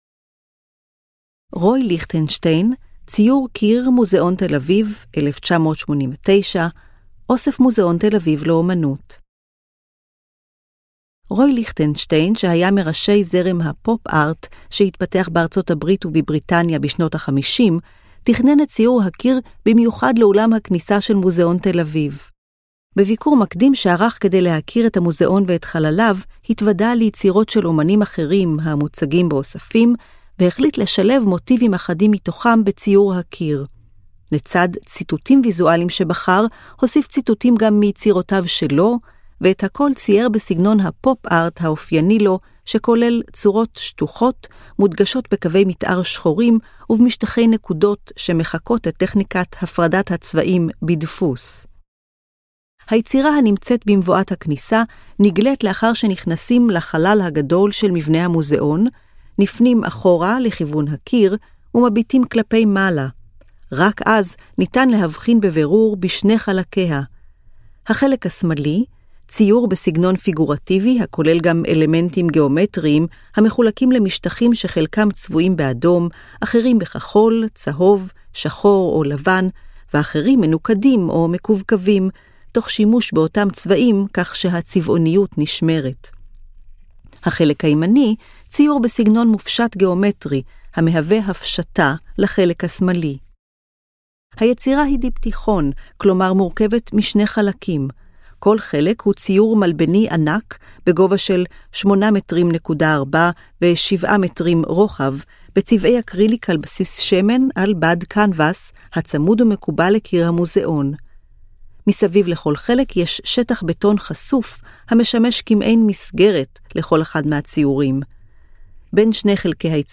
היצירות כולן הונגשו כאמור באמצעות: טקסט – תיאור מורחב המתאר את פרטי היצירה, אודיו – הקלטת התיאור המורחב אותו ניתן לשמוע במדריך הקולי של המוזיאון, גרפיקה טקטילית - הבלטה של היצירות באמצעות מדפסות ברייל, ברייל – תרגום הטקסט המורחב לכתב ברייל.